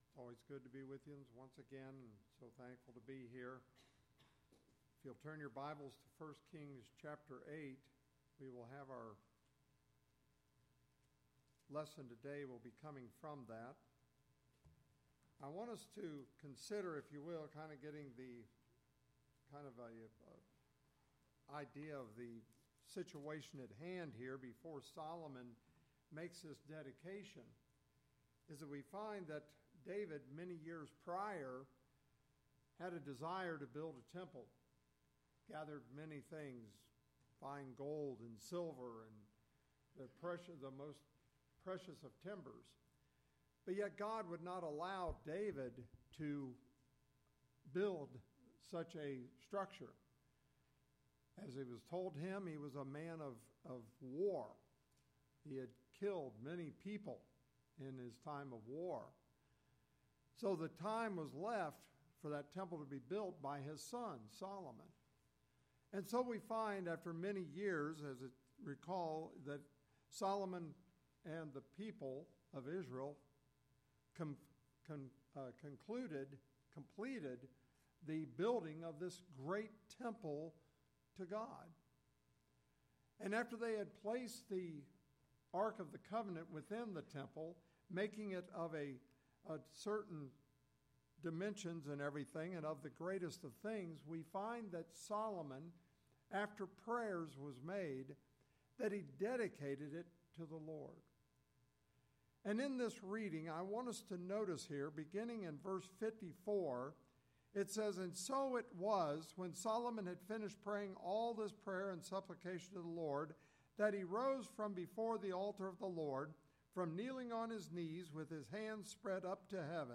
Lesson Recording